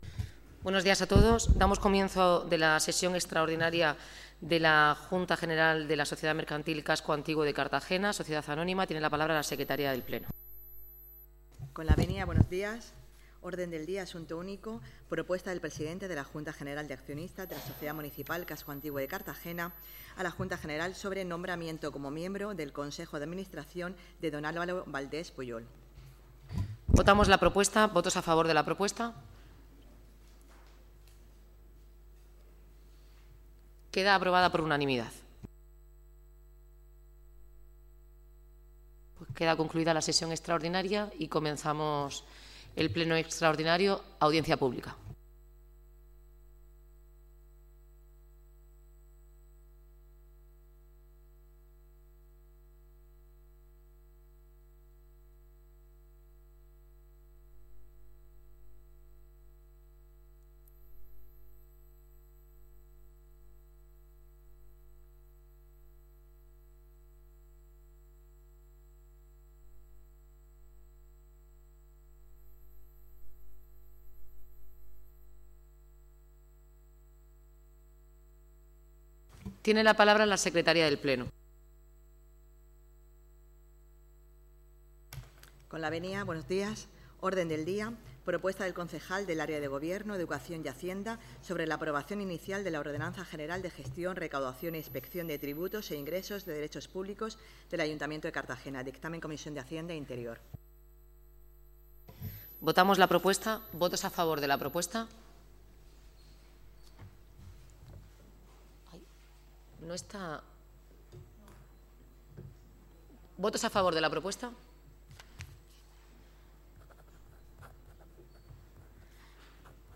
Audio: Pleno extraordinario de ordenanzas fiscales 2024 (MP3 - 43,99 MB)
El pleno de la corporación municipal, reunido este viernes 20 de octubre en sesión extraordinaria bajo la presidencia de la alcaldesa, Noelia Arroyo, ha dado luz verde por unanimidad a la aprobación inicial de la nueva ordenanza general de Gestión, Recaudación e Inspección de tributos e ingresos de derecho público del Ayuntamiento de Cartagena para 2024.